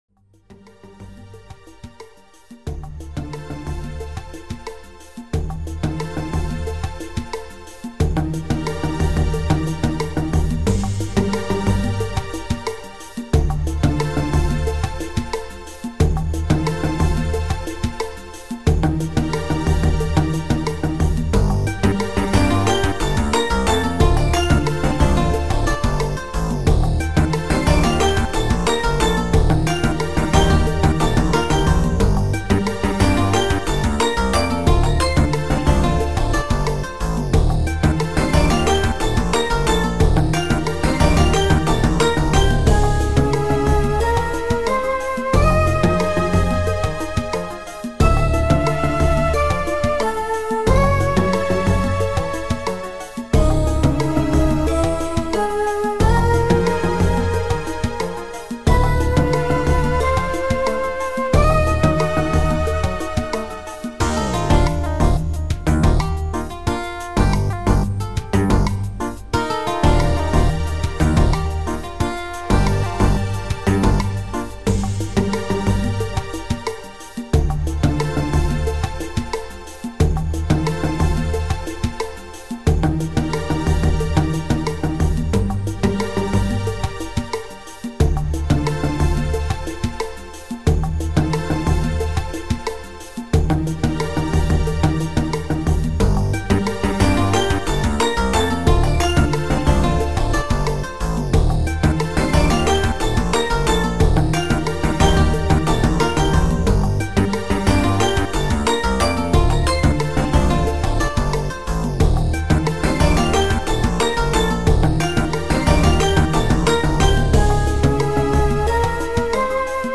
sounds like a cross between sapphire bullets and cooling magnet.